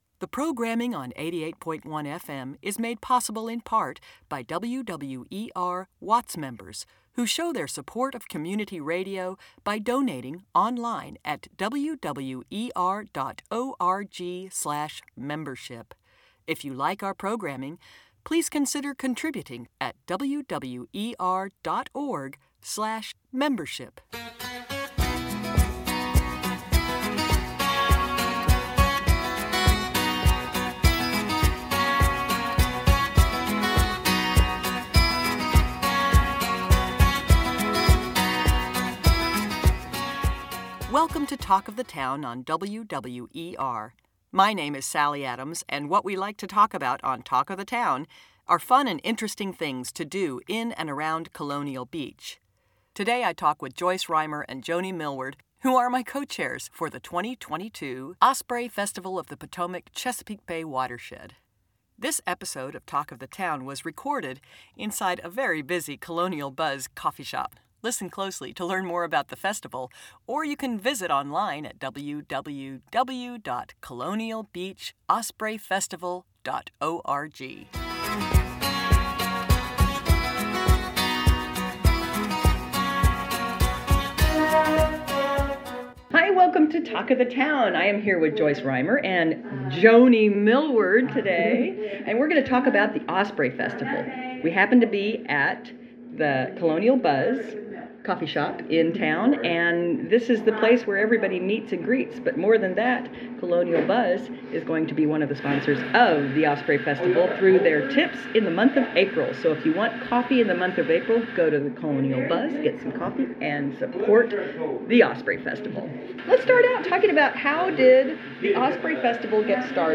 A conversation with the organizers of the 2022 Osprey Festival in Colonial Beach